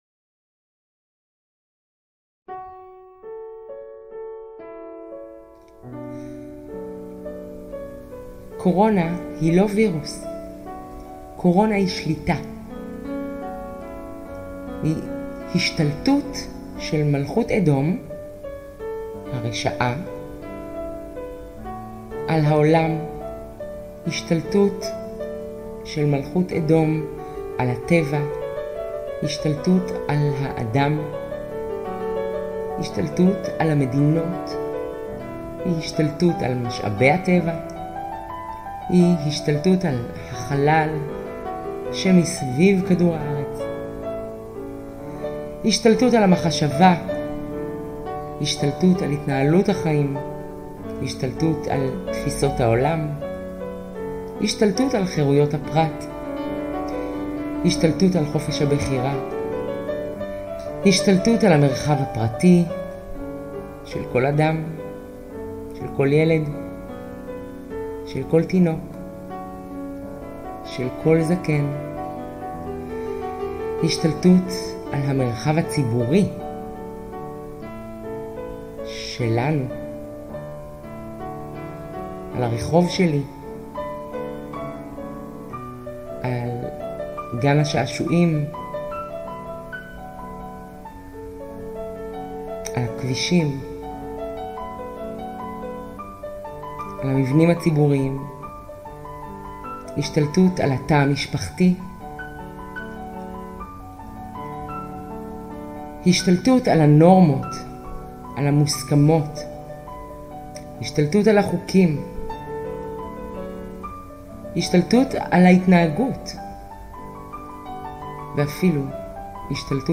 גרסא קצרה להרצאה